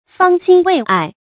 注音：ㄈㄤ ㄒㄧㄥ ㄨㄟˋ ㄞˋ
方興未艾的讀法